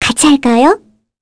Mirianne-Vox_Skill6_kr.wav